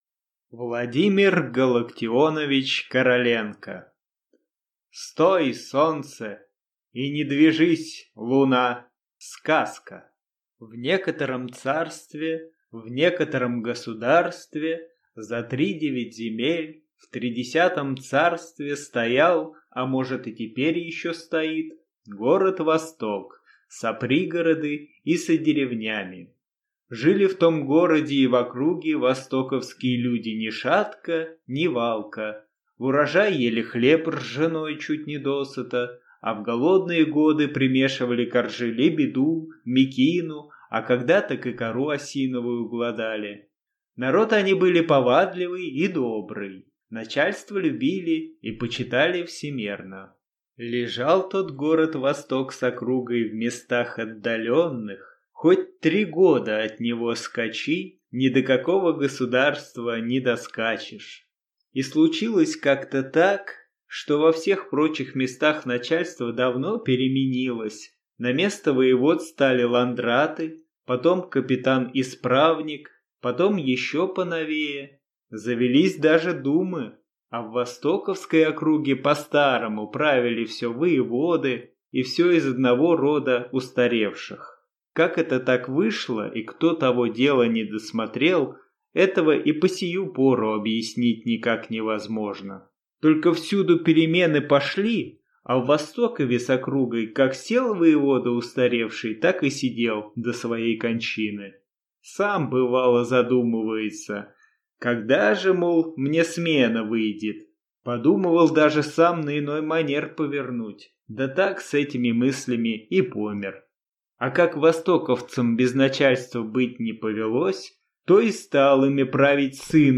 Аудиокнига Стой, солнце, и не движись, луна!